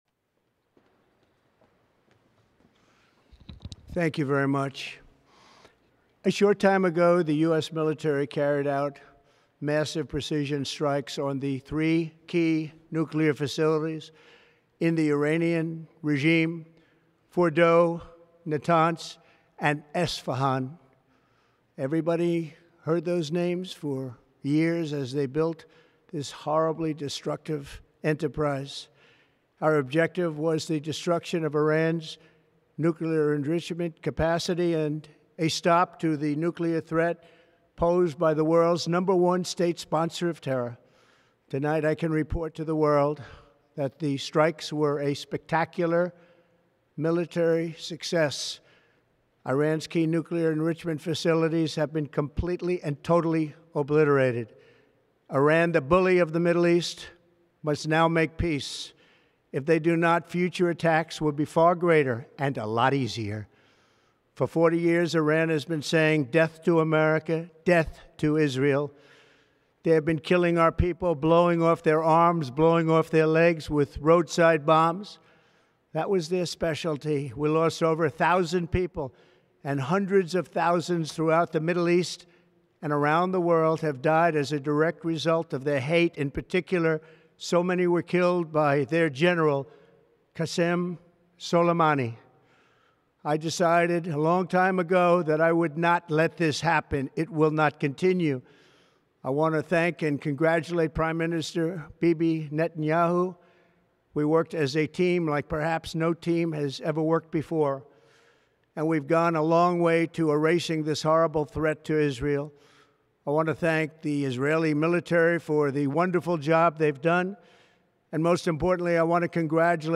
Presidential Speeches | Donald Trump Presidency